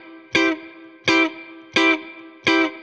DD_StratChop_85-Cmaj.wav